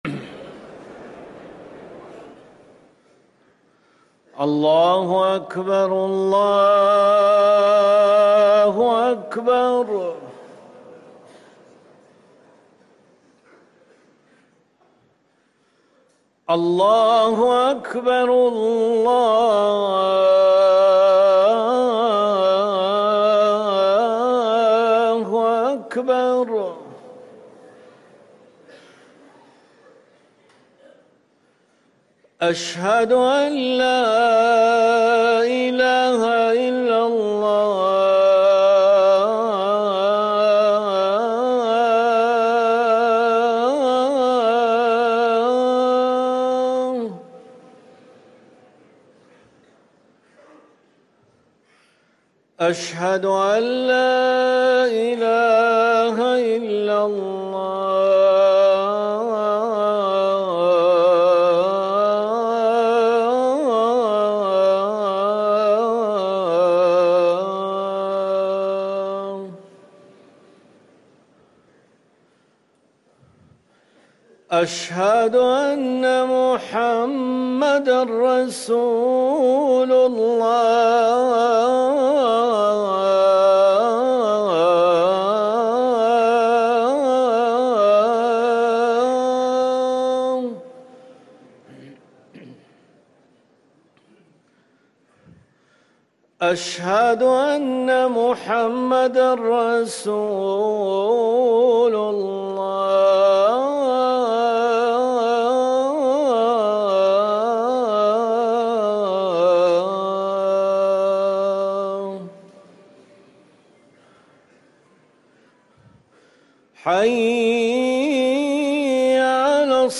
أذان الظهر